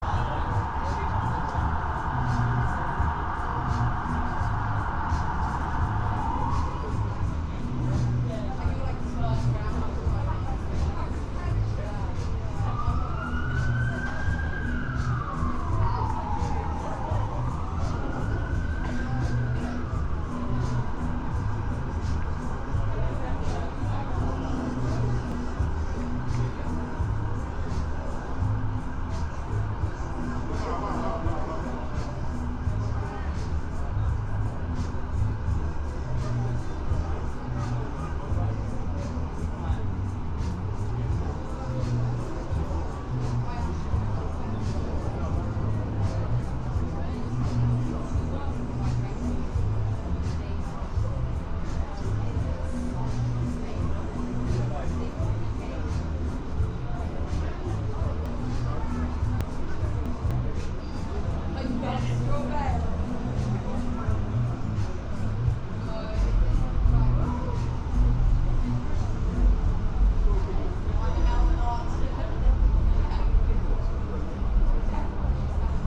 Live from Soundcamp: Stop the Eviction (Audio) Nov 15, 2023 shows Live from Soundcamp Live audio from Old Paradise Yard, Waterloo. Gathering against eviction.